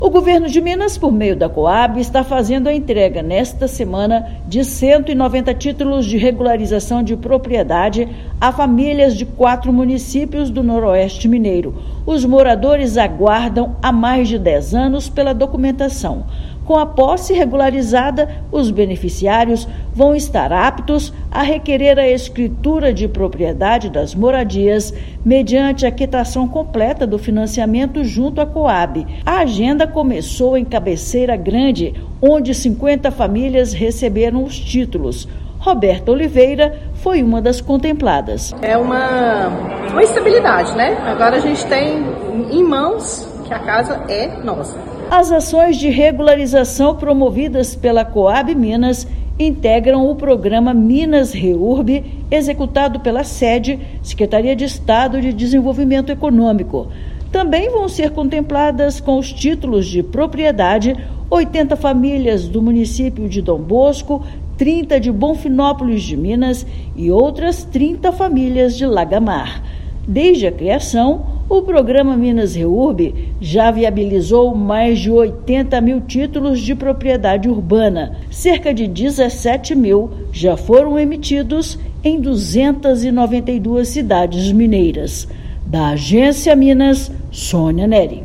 Entregas da Cohab Minas vão até sexta-feira (25/7) nos municípios de Cabeceira Grande, Dom Bosco, Bonfinópolis de Minas e Lagamar. Ouça matéria de rádio.